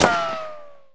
bullet_rico_rock_01.wav